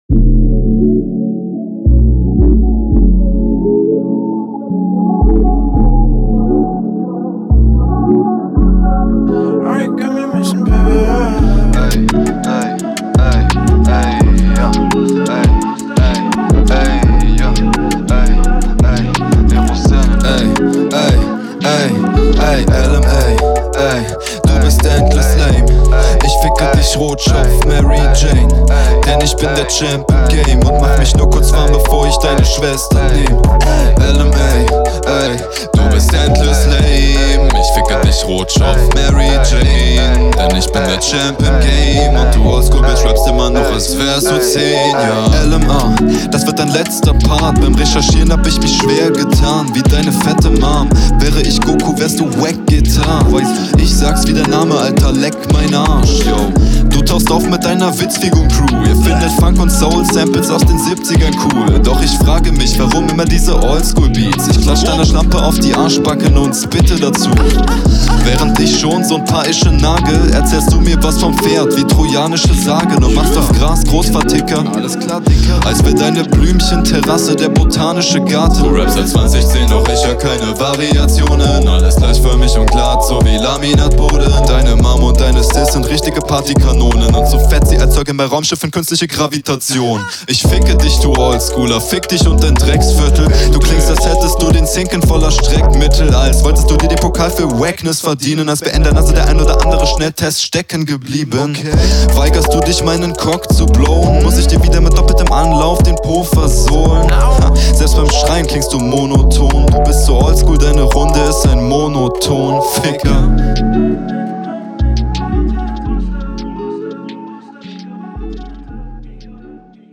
nicer Sound ey, ey, ey. richtig guter Mix. Delivery auch richtig gut. wirkt sehr professionell.
Flow: interessant. du wandelst dich hier ganz schön.